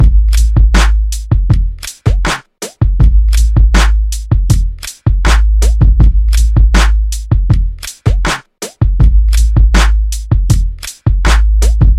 鼓乐循环播放 " 动态鼓乐循环播放
标签： 工作室 鼓包 工作室 鼓设置
声道立体声